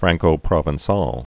(frăngkō-prōvän-säl, -prŏvən-)